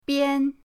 bian1.mp3